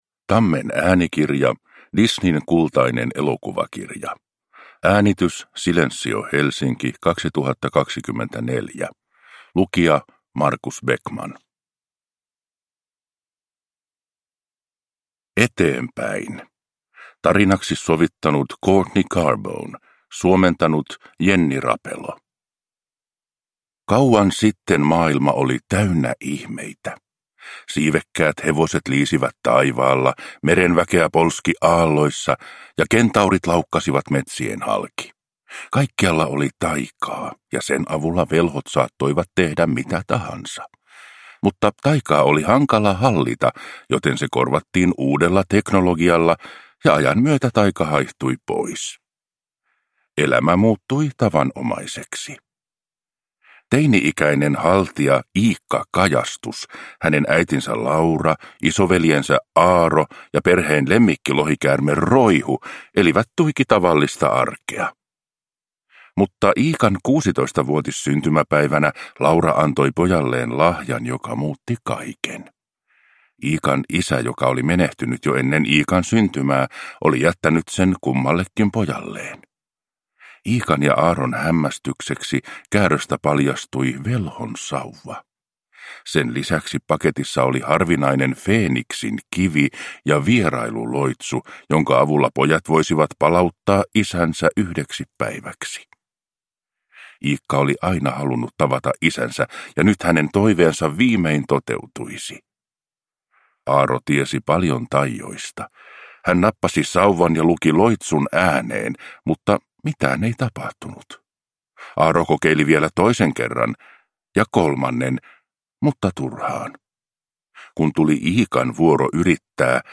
Disneyn kultainen elokuvakirja – Ljudbok